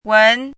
wén
拼音： wén
注音： ㄨㄣˊ